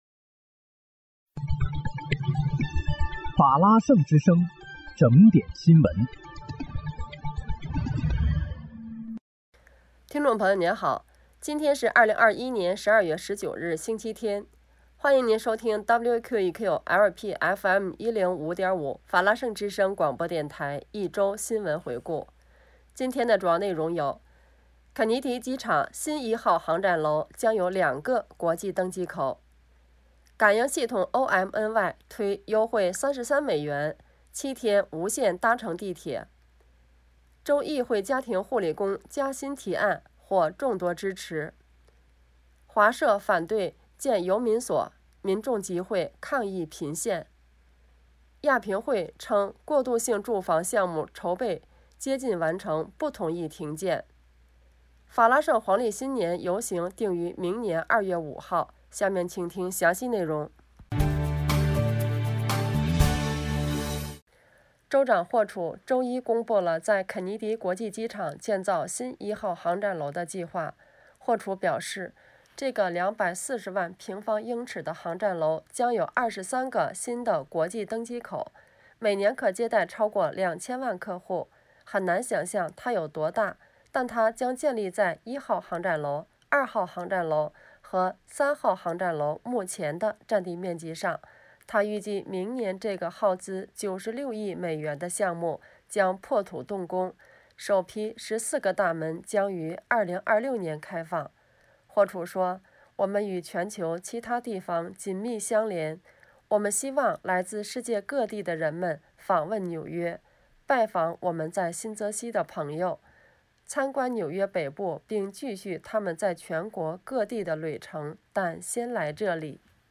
12月19日（星期日）一周新闻回顾